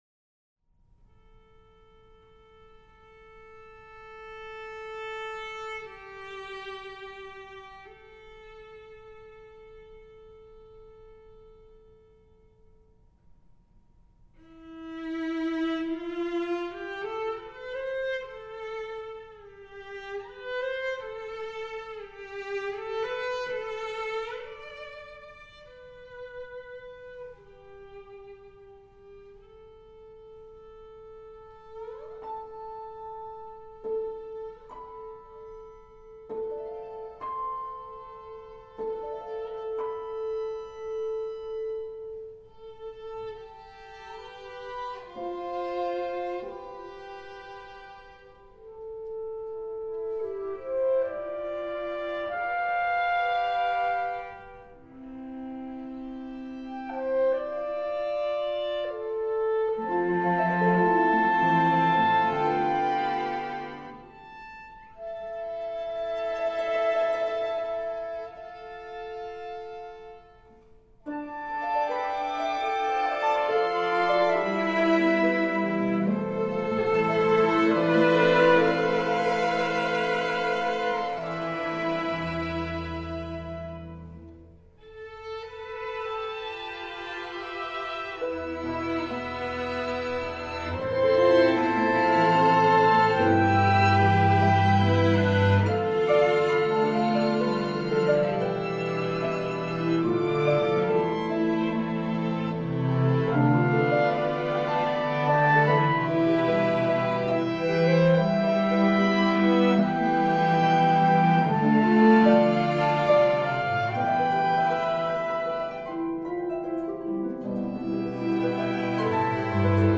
clarinet, harp, and string quintet